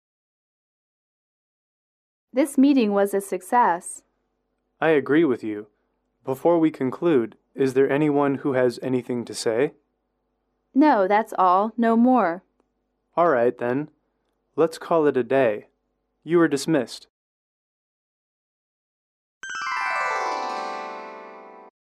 英语口语情景短对话04-4：会议结束语